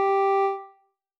添加三个简单乐器采样包并加载（之后用于替换部分音效）